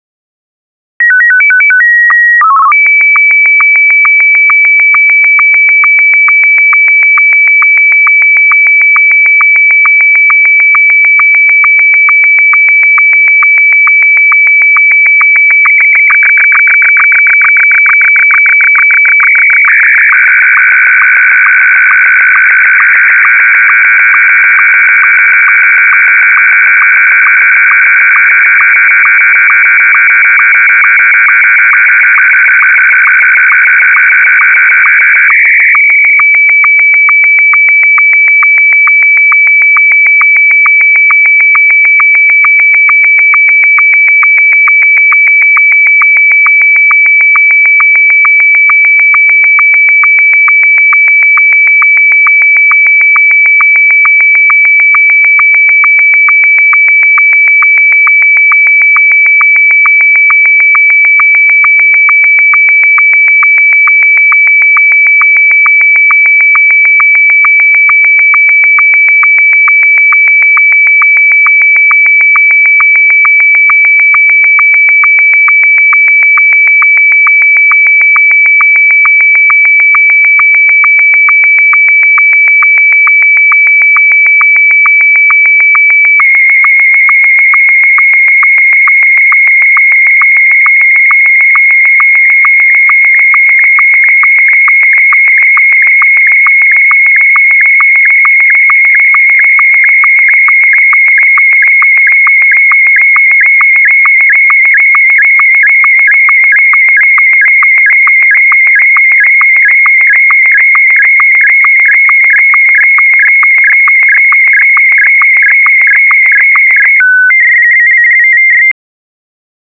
Pieep. Pieeeeep-pieeep.
Den alten Säcken unter uns - sowie Menschen in der Verwaltung ... - dürfte das Gepiepe bekannt vorkommen und Erinnerungen an Fax-Geräte hervorrufen. Und das ist gar nicht mal verkehrt, hier geht es um Schmalband-Fernsehen, beziehungsweise Amateurfunk-Fax oder Slow Scan Television (SSTV) im Englischen.
Was da passiert ist im Grunde recht simpel: Ein Bild wird zeilenweise abgetastet und jeder Punkt, beziehungsweise dessen Helligkeit, wird dann im Frequenzbereich von 1.500 bis 2.300 Hz kodiert übertragen und auf dem Empfänger entsprechend Schritt für Schritt dekodiert, also quasi "ausgedruckt".